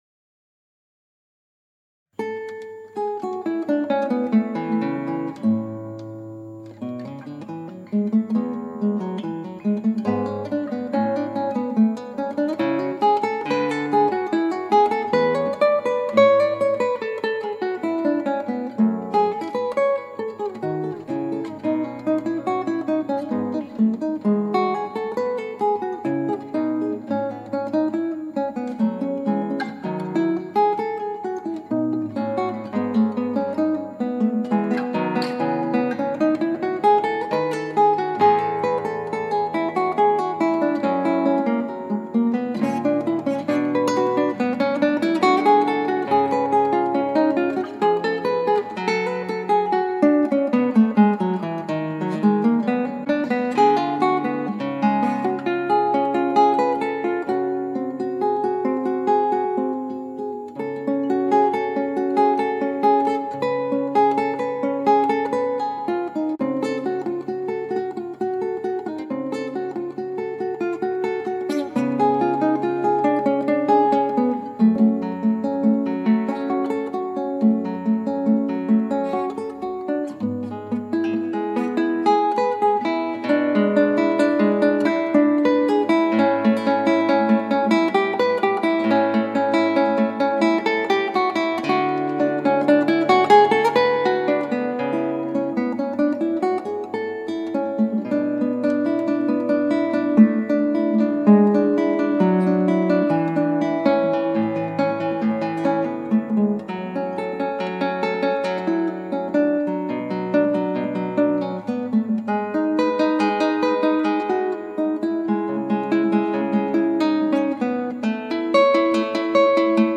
クラシックギター　ストリーミング　コンサート